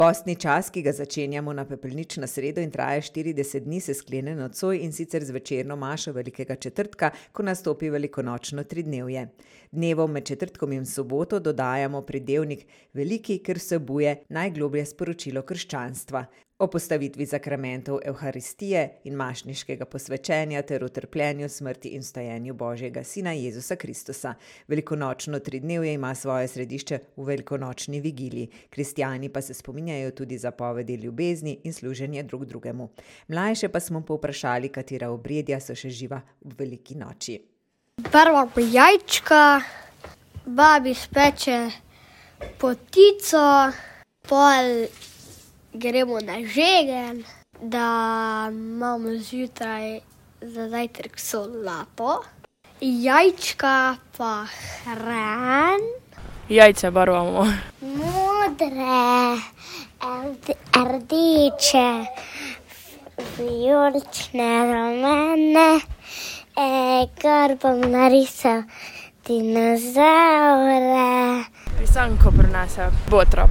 Poslušajte mlade ...
Mlajše smo povprašali, katera obredja so še živa ob veliki noči: